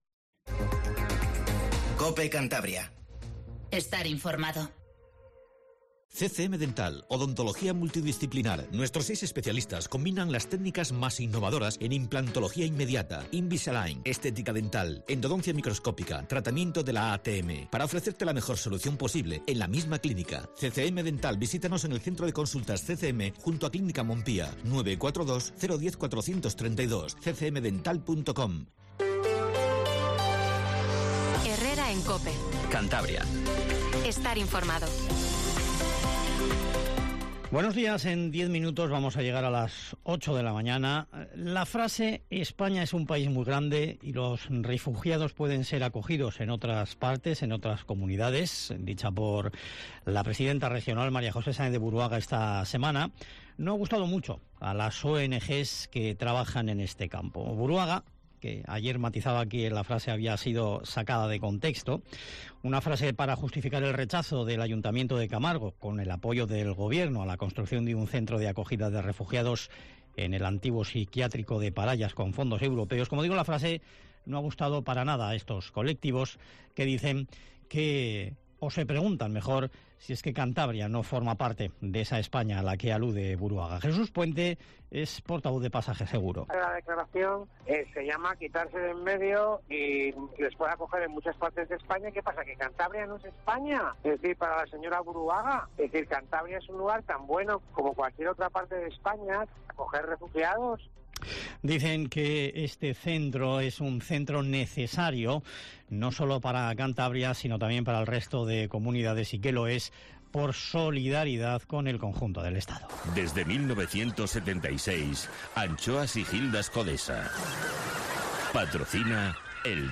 Informativo Matinal Cope 07:50